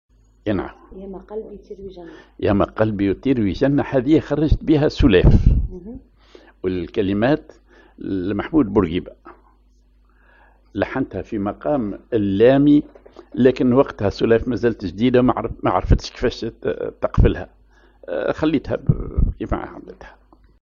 ar لامي
ar نصف وحدة أو دويك
أغنية